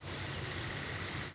American Redstart Setophaga ruticilla
Flight call description Typically an abrupt, upslurred "tswee" with a subtle two-parted character. Also a more distinctly two-parted "tswit" or a nearly monotone "tsee". Most variations have a distinctive clear, sweet quality.
Fig.1. Virginia August 24, 1993 (MO).
Bird calling in flight.